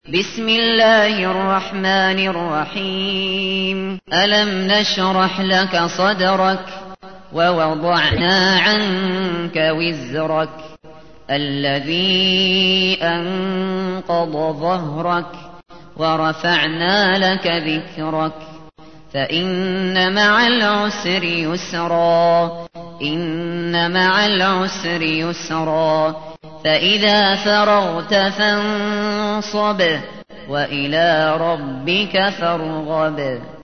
تحميل : 94. سورة الشرح / القارئ الشاطري / القرآن الكريم / موقع يا حسين